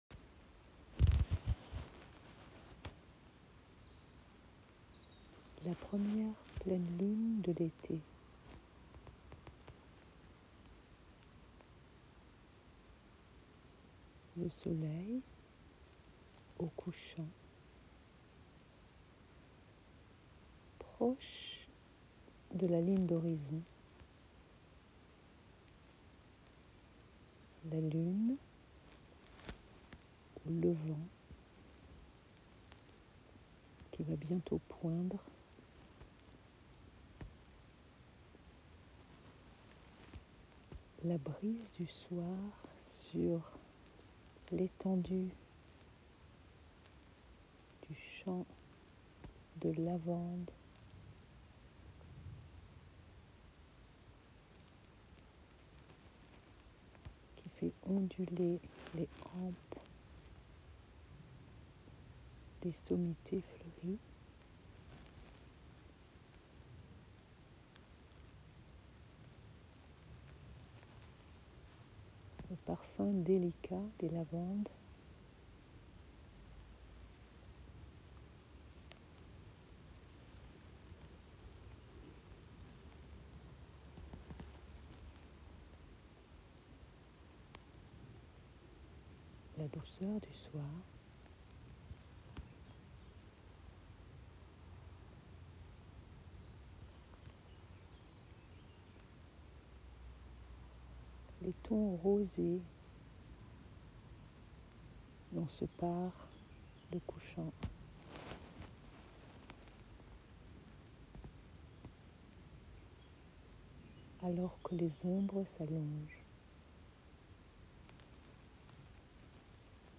le cercle de méditation de la pleine lune en Capricorne, elle a eu lieu à 20:40 heures le 24 juin 2021 alors que le portail du solstice se referme un espace nouveau où pressentir/découvrir les premières manifestations de l’unité